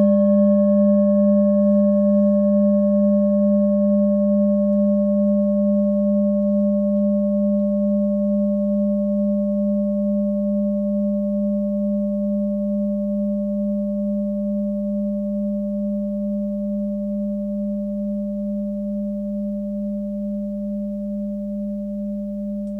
Klangschalen-Typ: Bengalen
Klangschale Nr.2
Gewicht = 1190g
Durchmesser = 19,4cm
(Aufgenommen mit dem Filzklöppel/Gummischlegel)
klangschale-set-1-2.wav